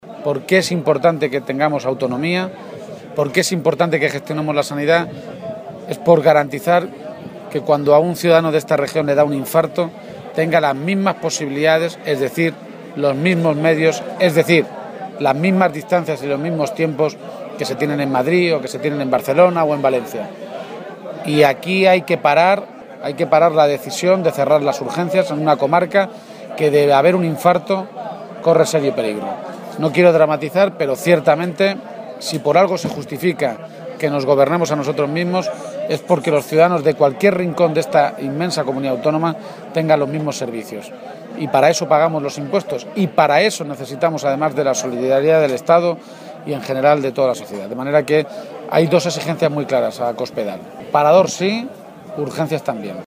Momento de la intervención de García-Page
García-Page ha lanzado estas dos peticiones a Cospedal durante un encuentro con alcaldes y concejales socialistas de la comarca de Molina, que se ha celebrado en Rueda de la Sierra, en la que ha animado a los ediles del PSOE a “seguir luchando por la defensa de esta tierra, porque, por lo visto hasta ahora, Cospedal difícilmente lo va a hacer, ya que no le importa ni Castilla-La Mancha, ni Guadalajara, ni Molina de Aragón y su comarca”.